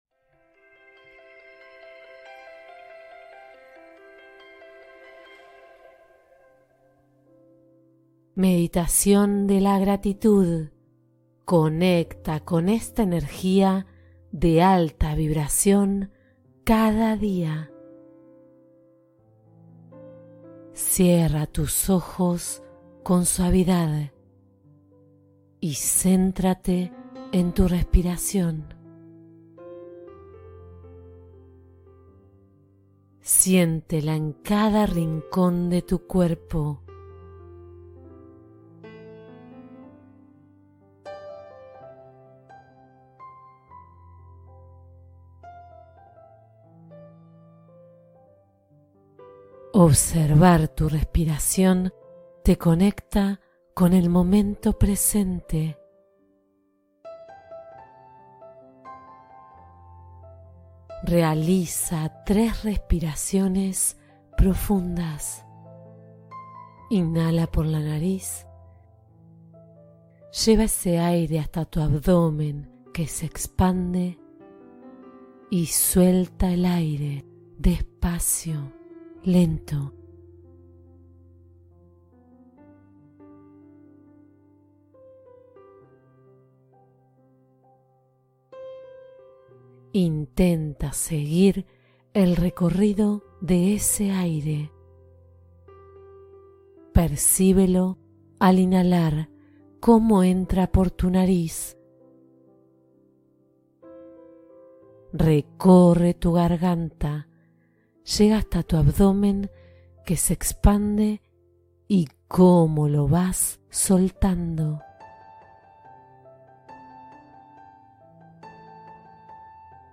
Gratitud en 10 Minutos: Meditación para Elevar el Estado Interior